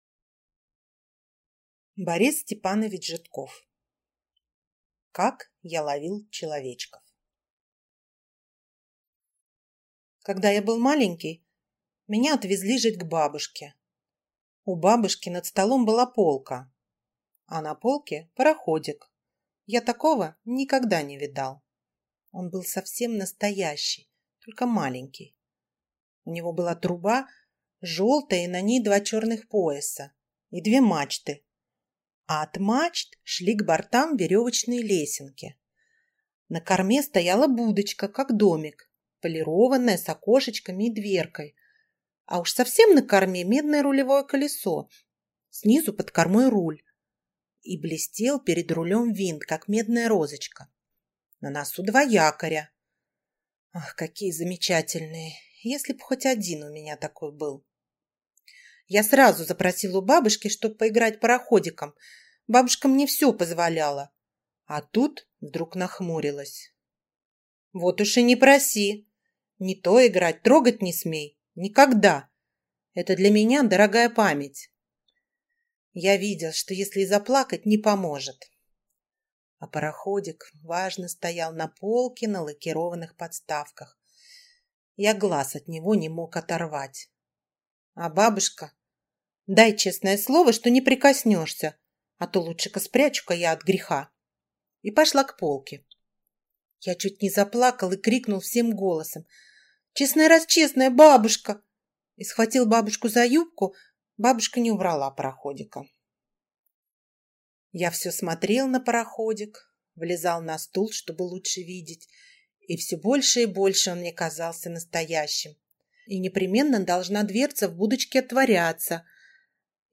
Аудиокнига Как я ловил человечков | Библиотека аудиокниг